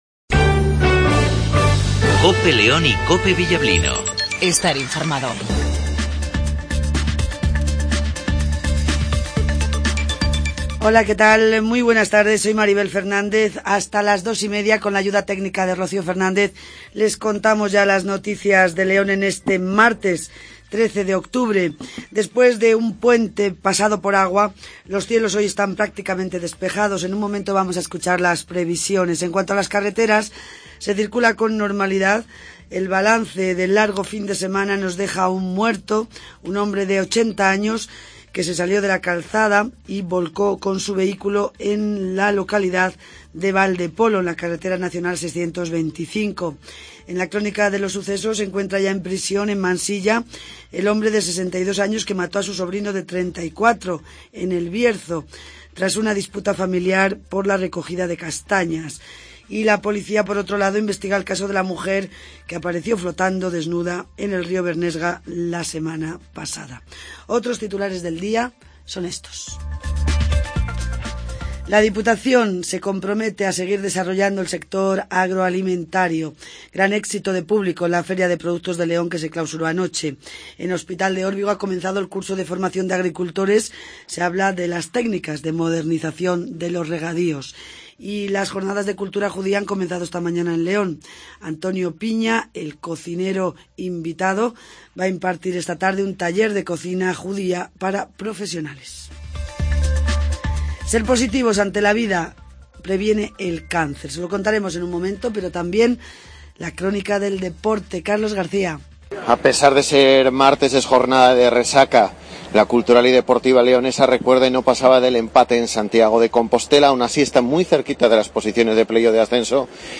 INFORMATIVO MEDIODIA